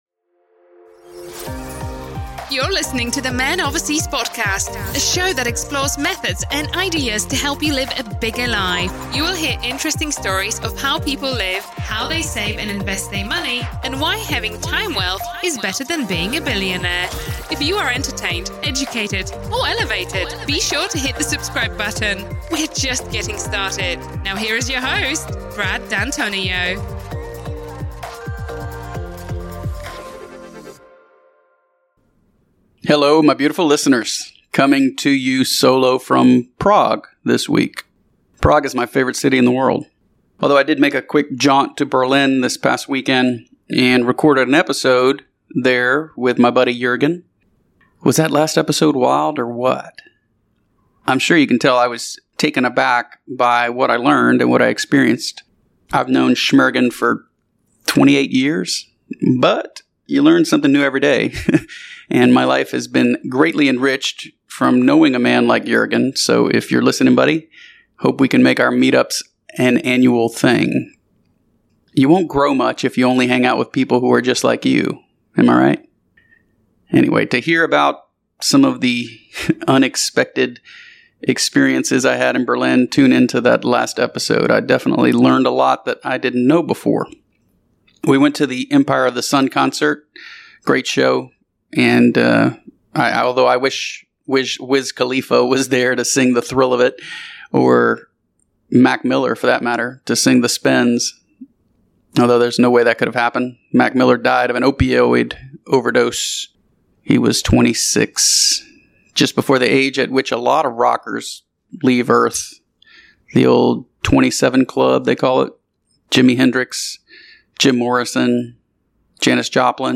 Coming to you solo from Prague—my favorite city in the world.